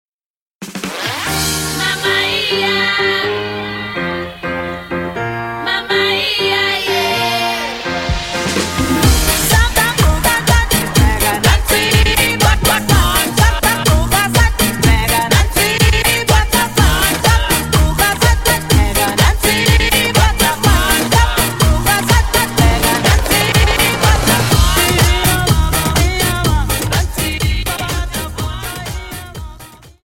Cha Cha 31 Song